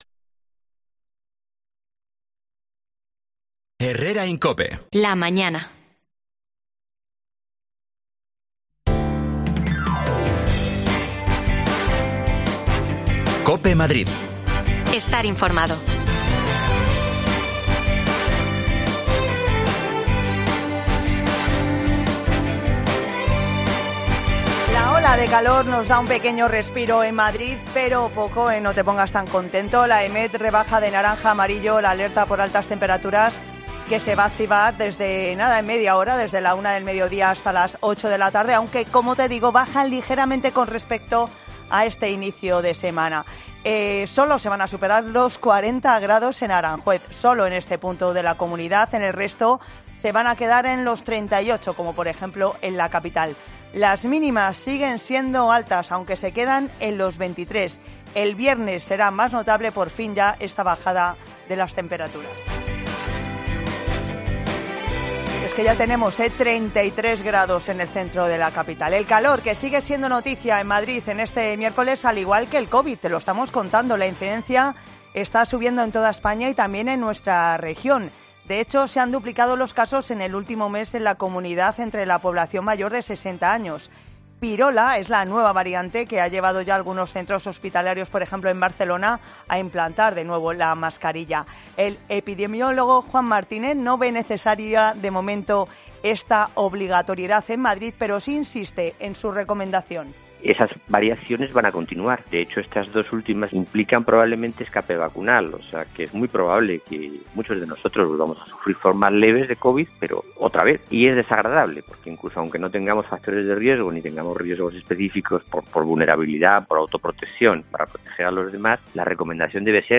Las desconexiones locales de Madrid son espacios de 10 minutos de duración que se emiten en COPE , de lunes a viernes.
Te contamos las últimas noticias de la Comunidad de Madrid con los mejores reportajes que más te interesan y las mejores entrevistas , siempre pensando en el ciudadano madrileño.